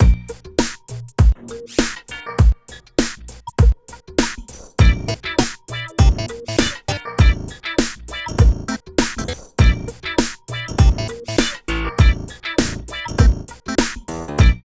66 LOOP   -R.wav